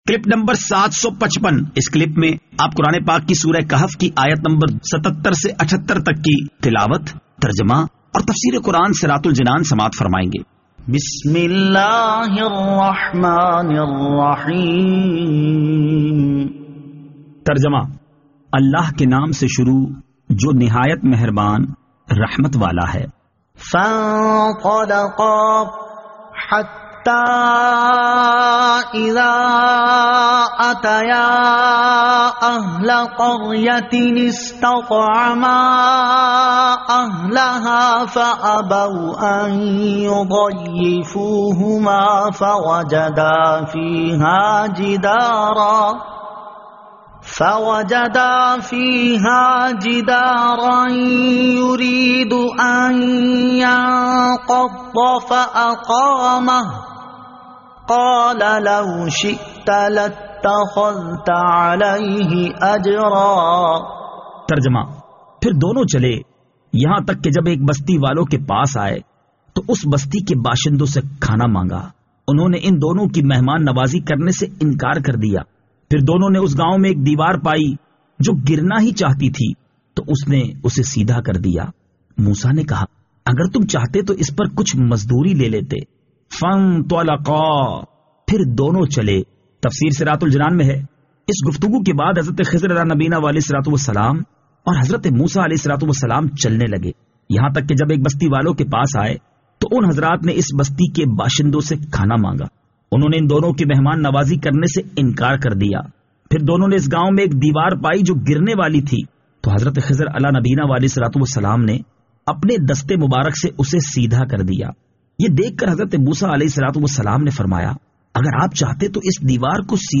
Surah Al-Kahf Ayat 77 To 78 Tilawat , Tarjama , Tafseer